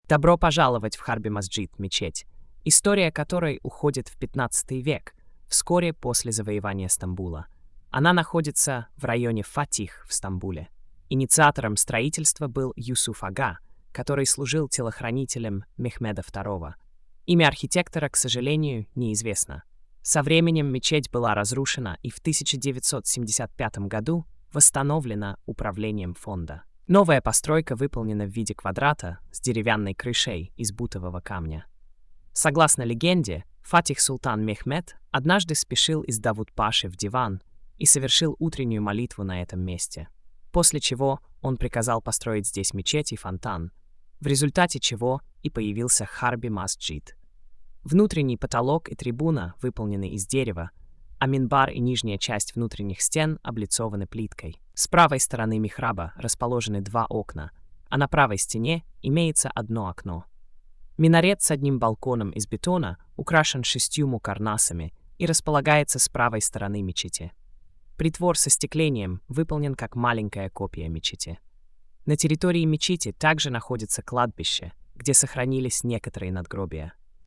Аудиоповествование